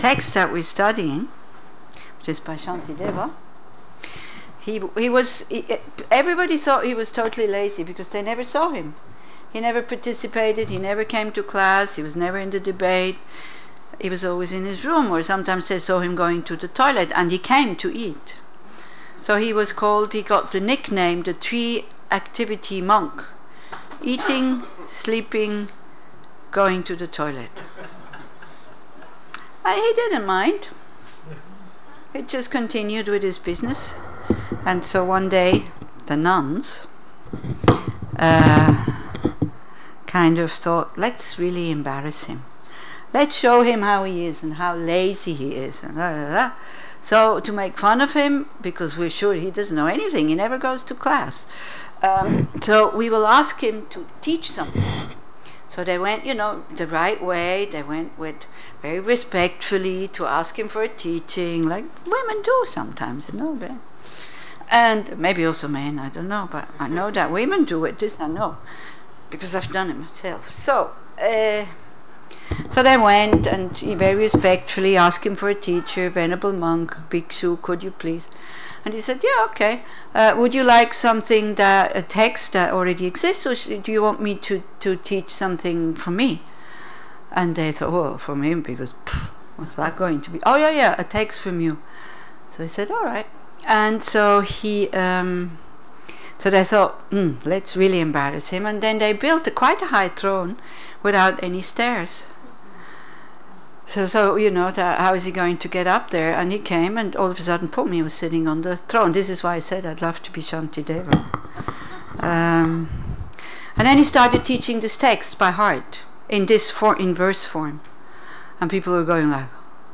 קורס יום כיפור 25/9/15 היטהרות 7 Q&A